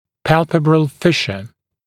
[‘pælpəbrəl ‘fɪʃə][‘пэлпэбрэл ‘фишэ]щель век, глазная щель